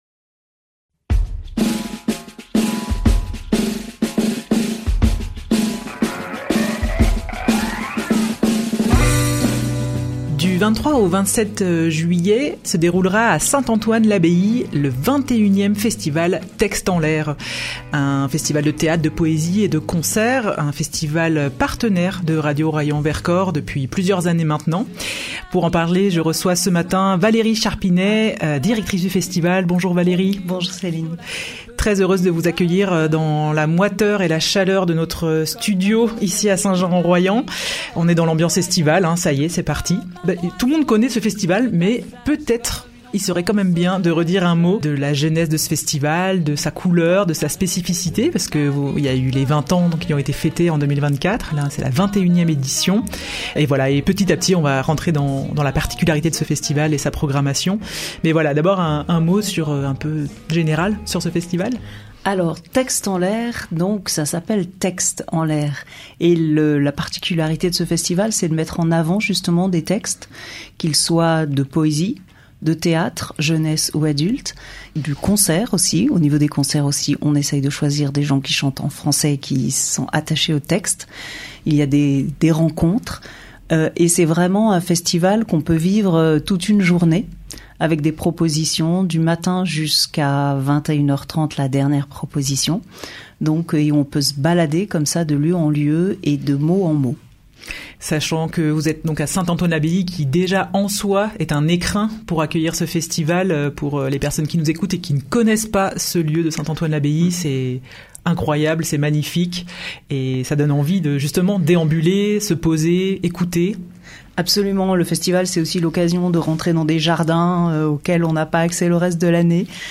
ITW-VC-TXT-en-lAIR-montee.mp3